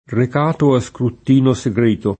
rek#to a Skrutt&no Segr%to] (G. Villani) — dell’uso più ant. altre varianti: scrutino [